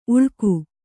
♪ uḷku